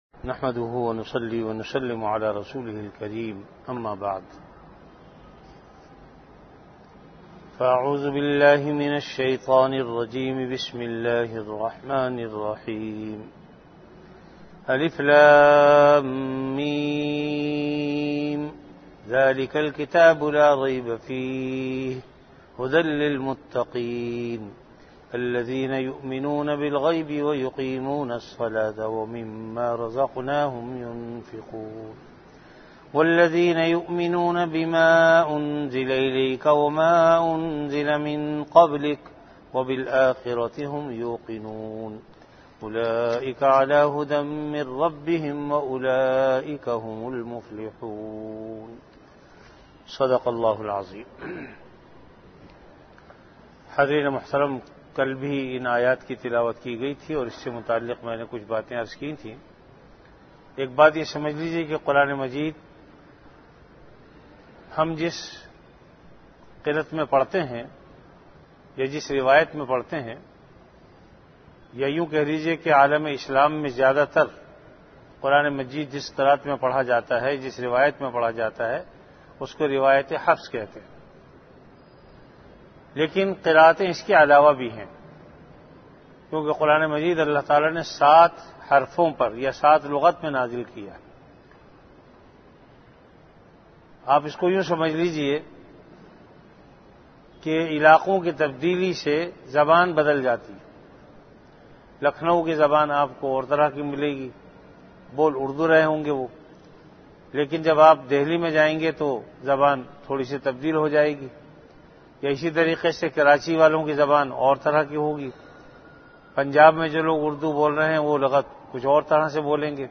Delivered at Jamia Masjid Bait-ul-Mukkaram, Karachi.
Dars-e-quran · Jamia Masjid Bait-ul-Mukkaram, Karachi